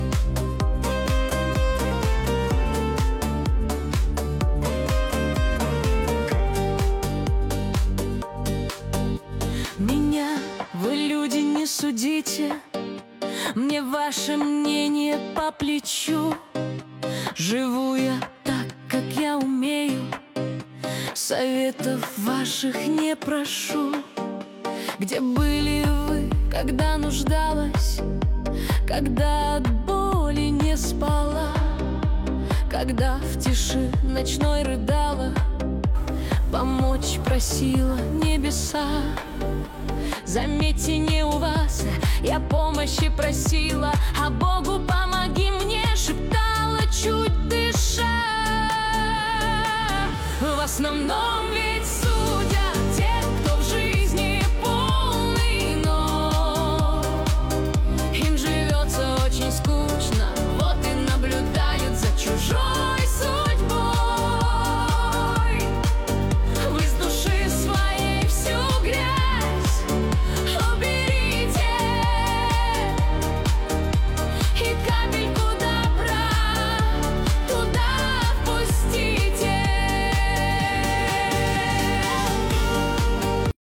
Качество: 320 kbps, stereo
Стихи, Нейросеть Песни 2025